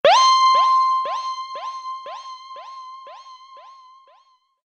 Kategorien Alarm